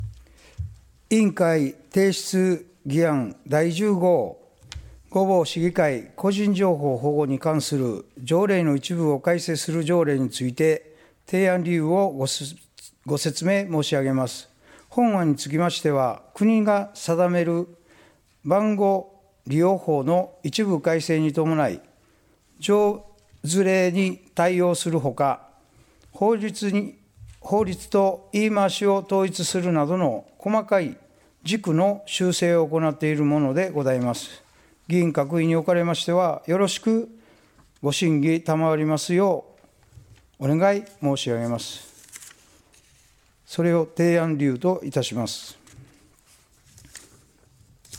議会運営委員長の提案理由（委員会提出議案第10号）